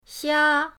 xia1.mp3